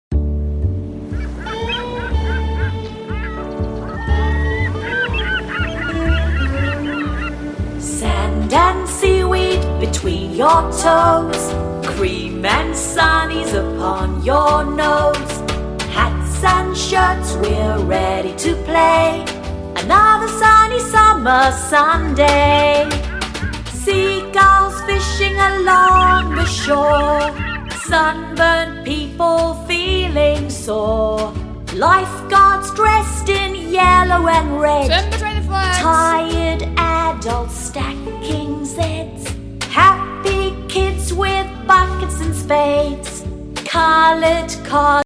-Mp3 Vocal Track